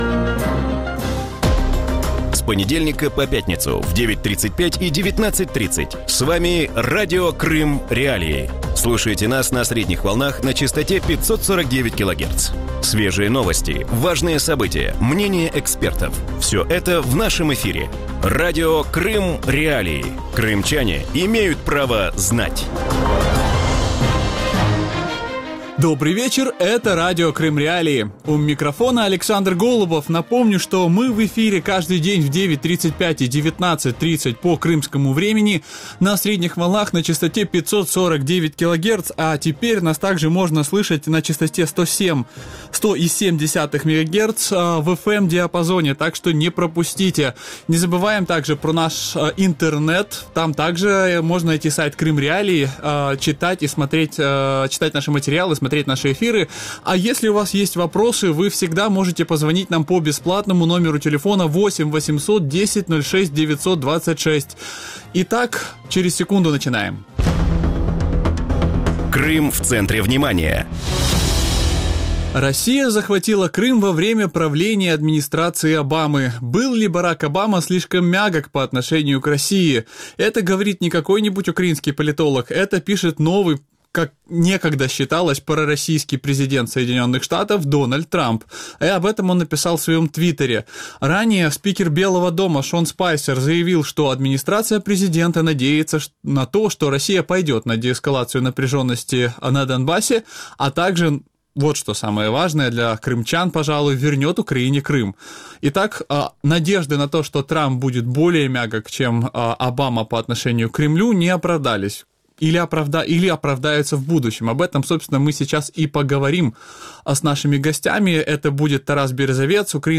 У вечірньому ефірі Радіо Крим.Реалії говорять про заяви президента США Дональда Трампа з приводу повернення Криму. Чи сформулювала нова адміністрація президента США позицію по Криму, якою буде стратегія взаємодії Білого дому з Кремлем і чи знімуть із Росії санкції?